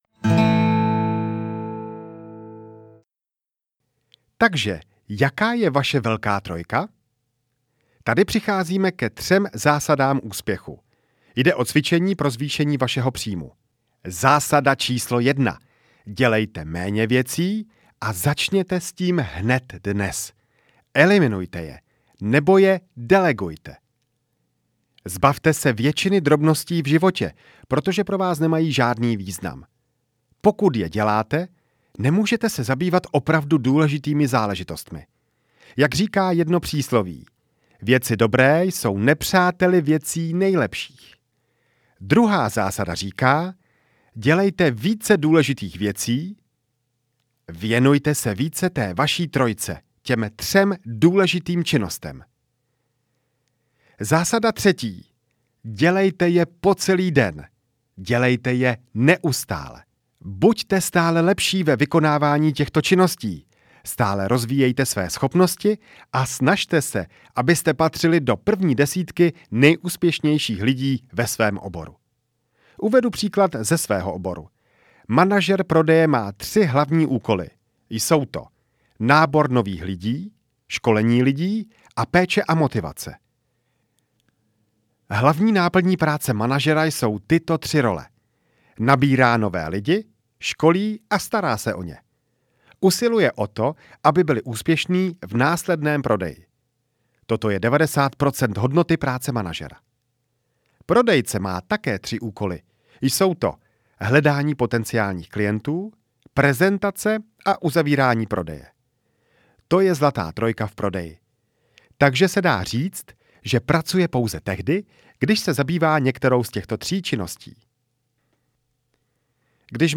To nejdůležitější z osobního rozvoje audiokniha
Ukázka z knihy